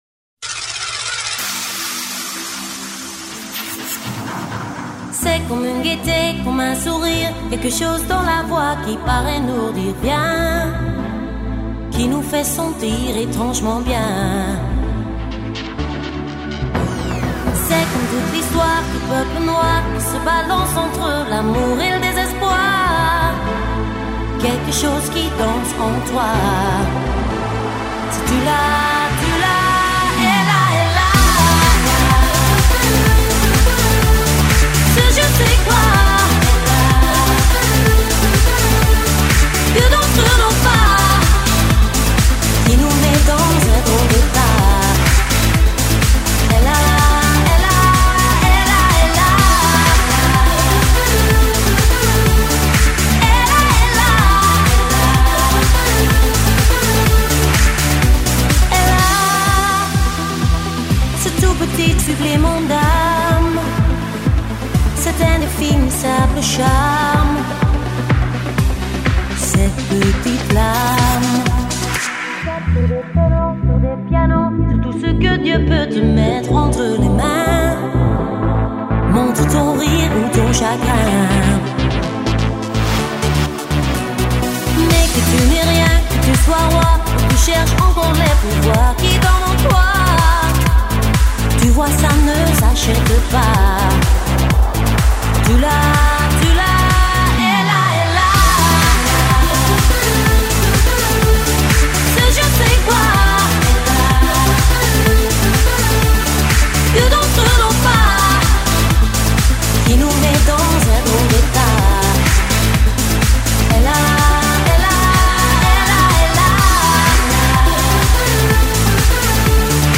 Жанр:Pop